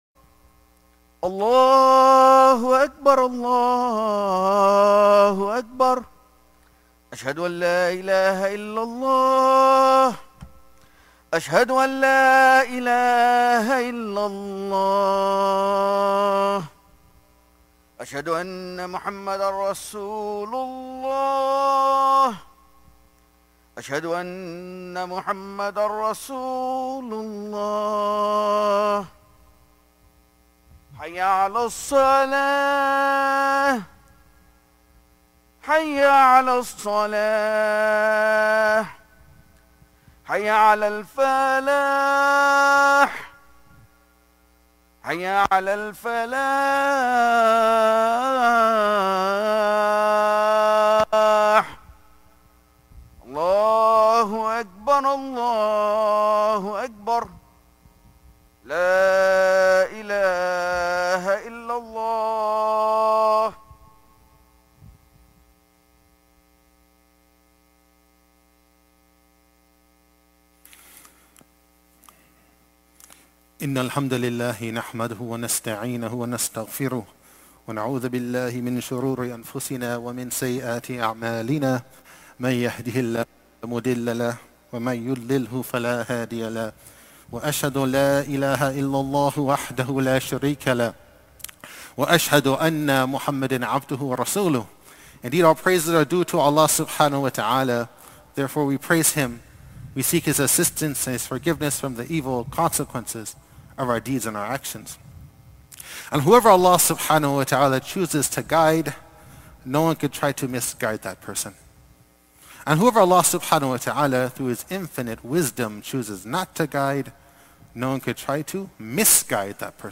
Friday Khutbah - "Money Management"